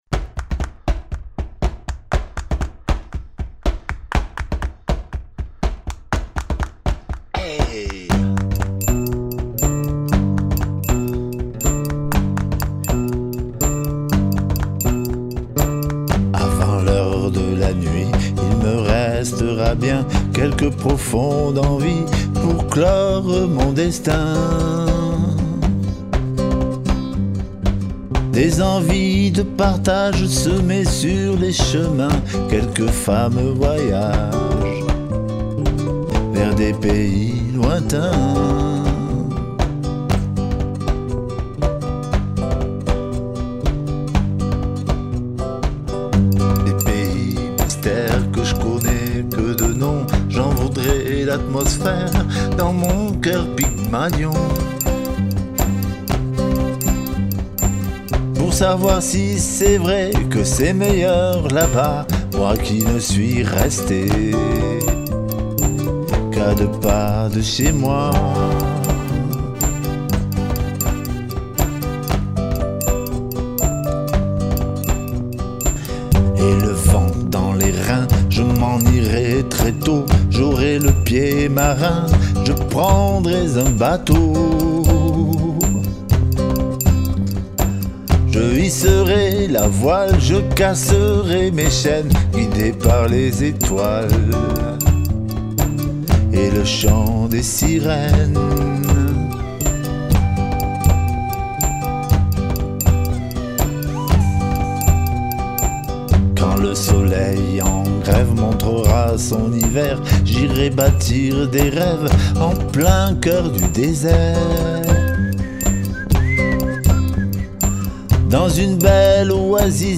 Leur naissance étant répartie sur une période de 30 ans, la qualité d'enregistrement n'est pas égale.